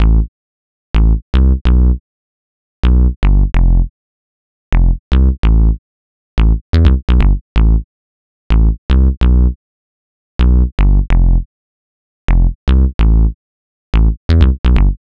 • Techno Melodic Bass Detroit.wav
Techno_Melodic_Bass_Detroit_eUT.wav